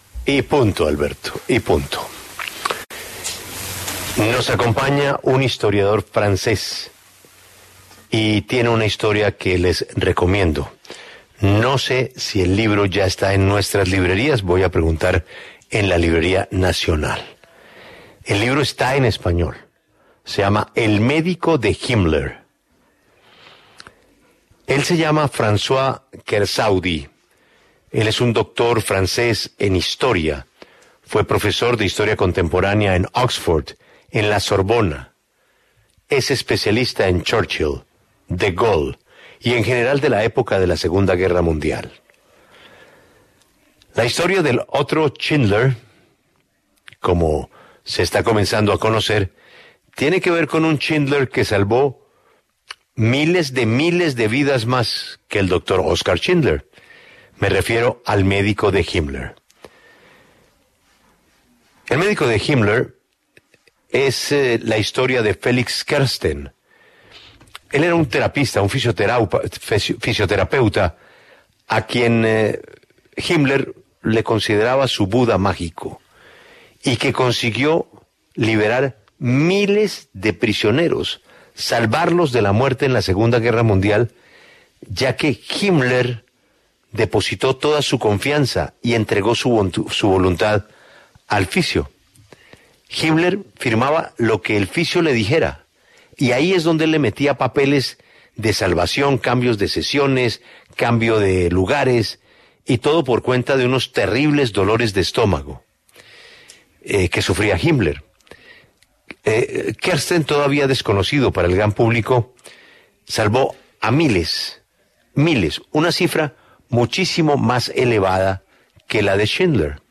François Kersaudy, historiador francés, especialista en historia diplomática y militar, habló en La W a propósito del lanzamiento de su libro “El médico de Himmler”.